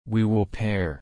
/pɛə(ɹ)/